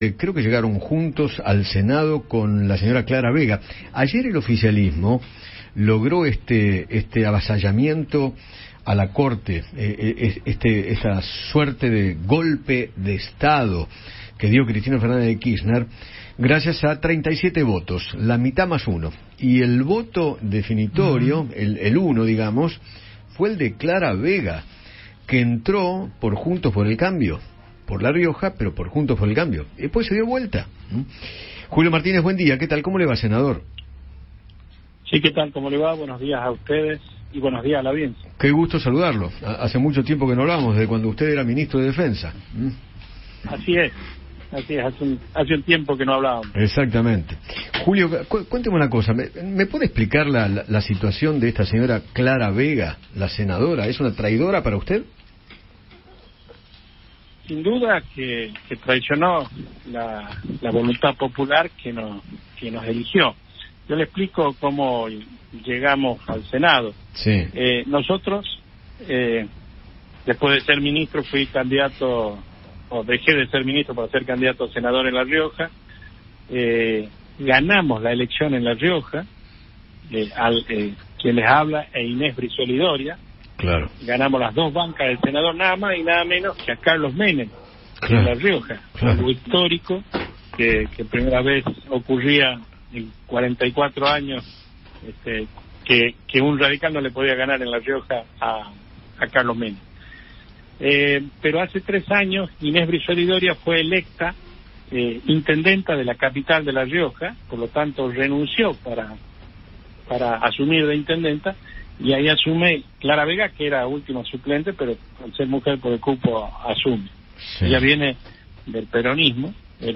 Julio Martínez, senador por la Unión Cívica Radical, dialogó con Eduardo Feinmann tras la votación en el Senado sobre la conformación del Consejo de la Magistratura, que terminó siendo favorable para el oficialismo.